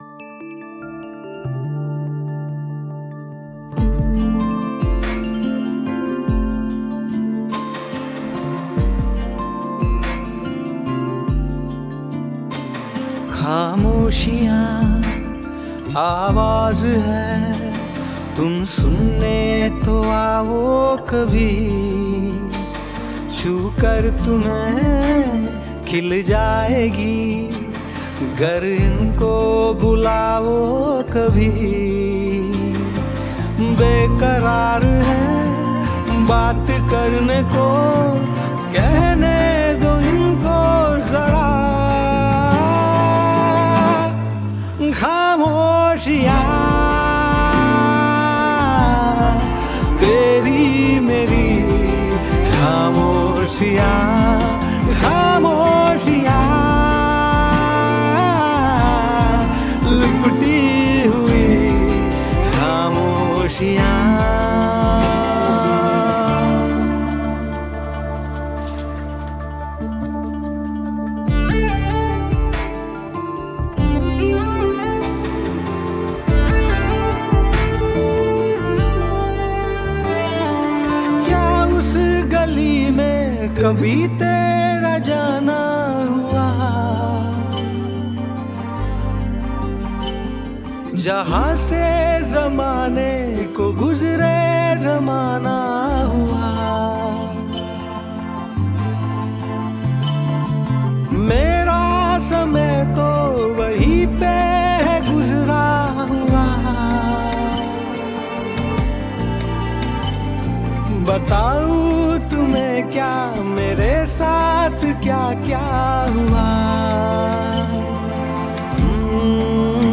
I used the instrumentals and have my vocals mixed in.